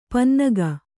♪ pannaga